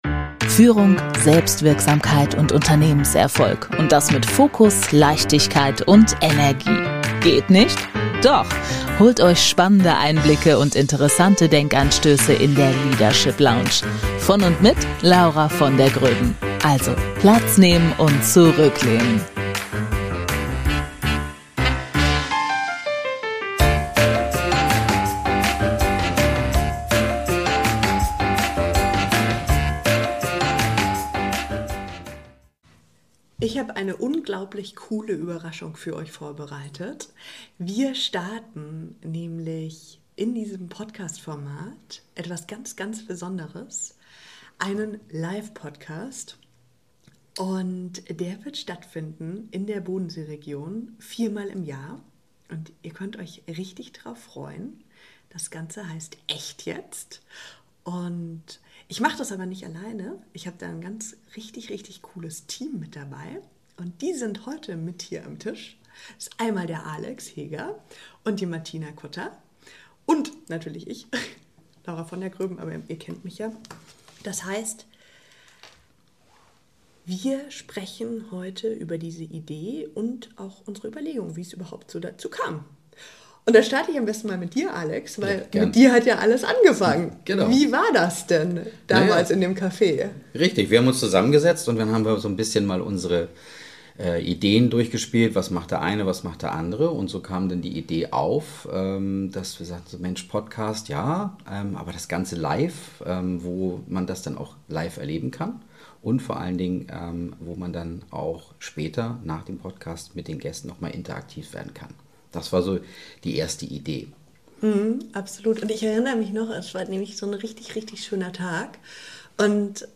Was passiert, wenn eine Psychologin, ein Event-Profi und eine Kommunikationsexpertin zusammen an einem Tisch sitzen – und beschließen, einen Raum für Gespräche zu schaffen, die wirklich etwas bewegen? In dieser ersten Folge geben wir einen Blick hinter die Kulissen von „echt jetzt!?“.